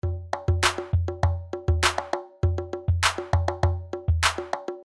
非洲鼓循环2
描述：非洲鼓的循环播放 民族鼓
Tag: 100 bpm Hip Hop Loops Drum Loops 826.96 KB wav Key : Unknown